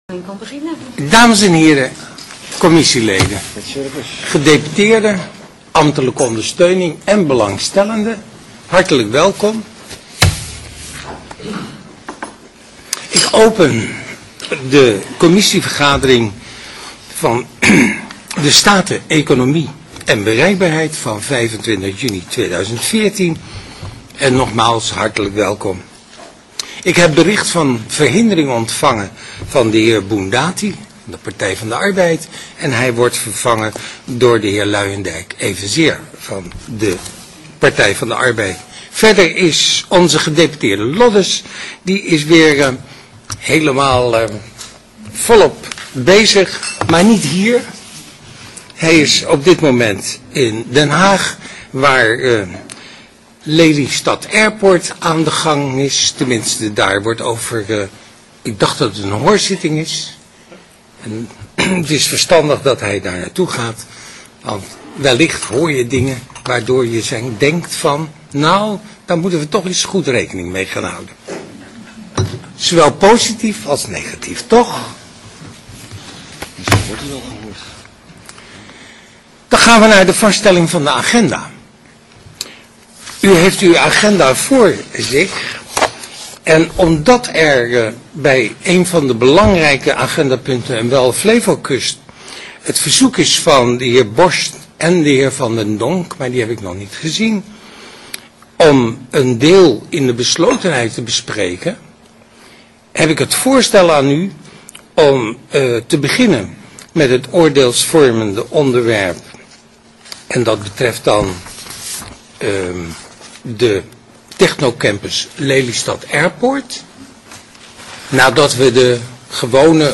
Download de volledige audio van deze vergadering
Locatie: Statenzaal